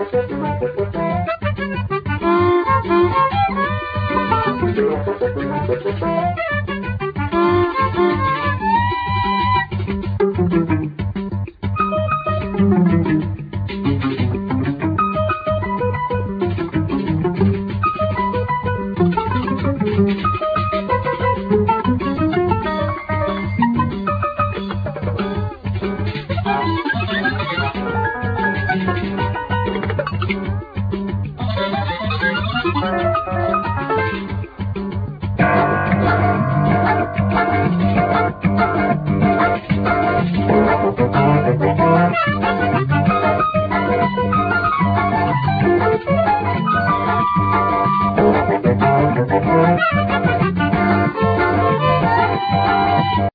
Basson, Oboe
Drums, Percussions, Radio
Bass, Guitar, Prepared guitar
Organ, Piano, Bass clarinet, Altsax, Xylophone, Percussions
Cello, electric cello, Voice
Piano, Synthsizer
Guitar, Vocals